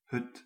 Ääntäminen
IPA: /ɦʏt/